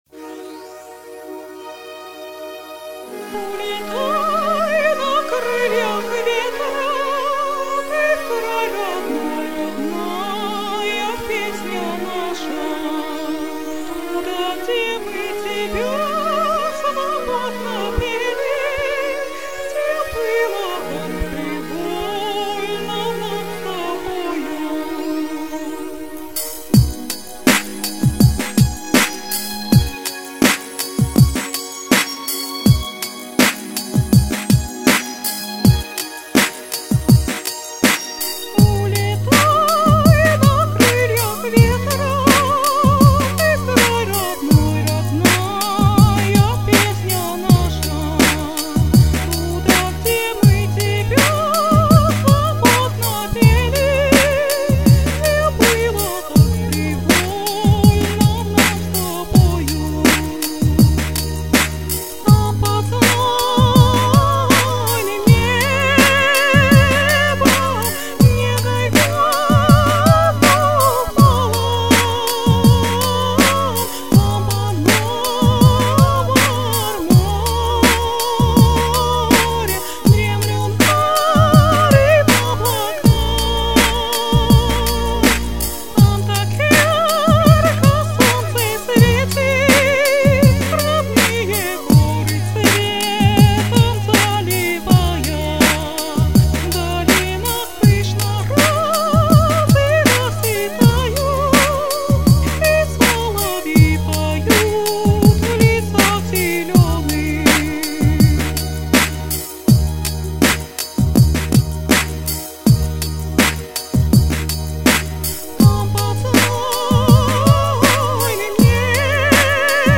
На самом деле тут два варианта - эстрадный и классический, так что это уже дело вкуса, кому что больше нравится.